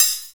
5214L CYM.wav